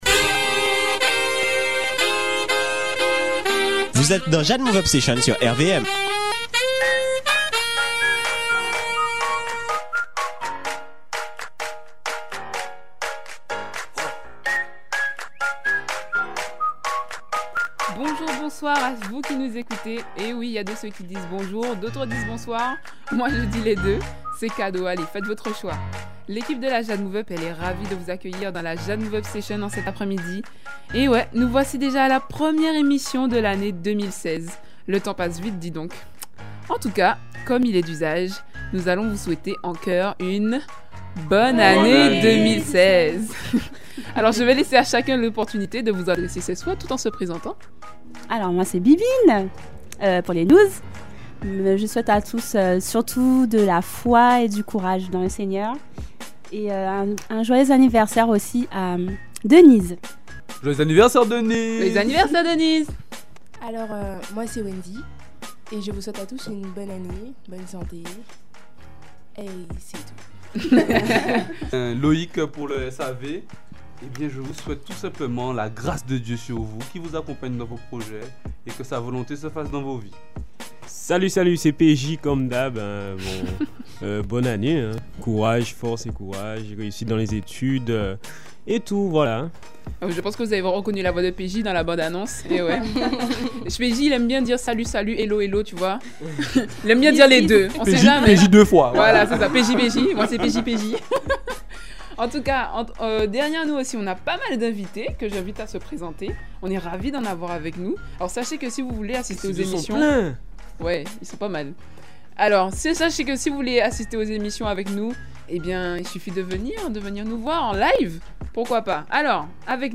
Comme d’habitude, cette équipe nous communique son enthousiasme, ses valeurs, sa joie, son amour, son espérance.
*Prochaine émission: samedi 20 février, en direct.